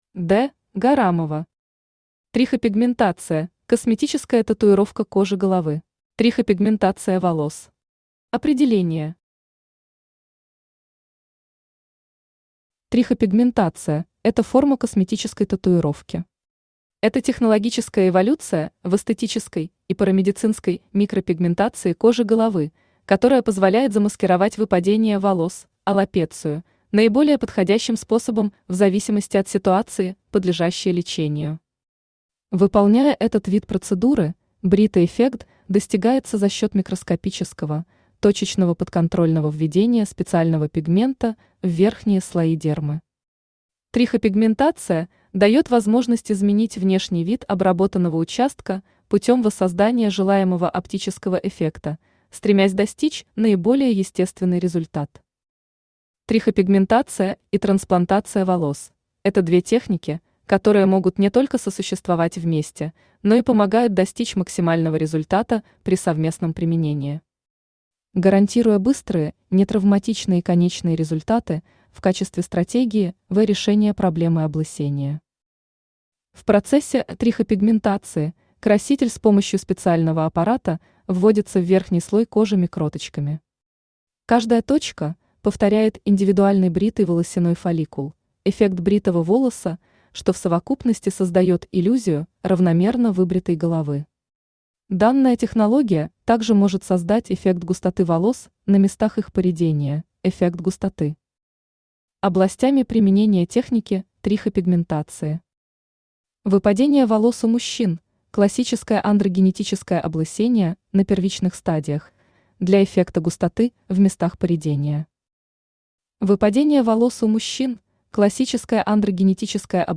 Аудиокнига Трихопигментация – косметическая татуировка кожи головы | Библиотека аудиокниг
Aудиокнига Трихопигментация – косметическая татуировка кожи головы Автор Д. Гарамова Читает аудиокнигу Авточтец ЛитРес.